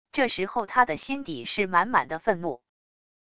这时候他的心底是满满的愤怒wav音频生成系统WAV Audio Player